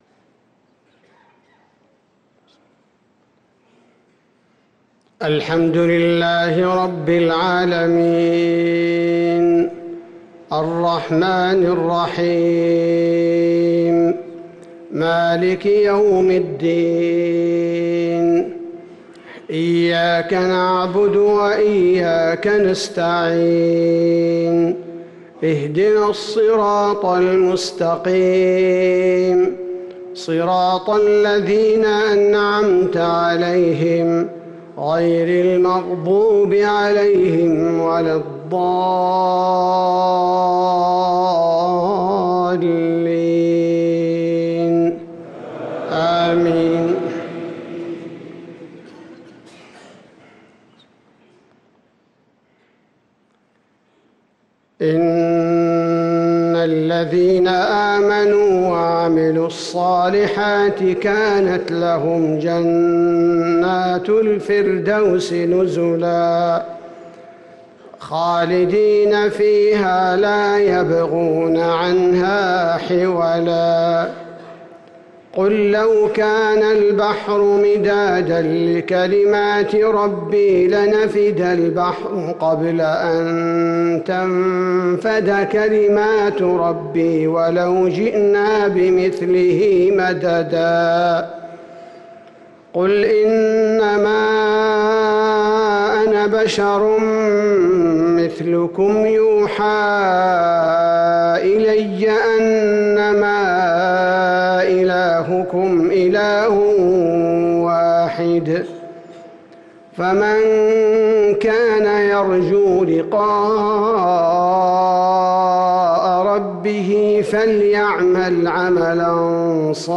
صلاة المغرب للقارئ أحمد بن طالب حميد 17 جمادي الأول 1445 هـ
تِلَاوَات الْحَرَمَيْن .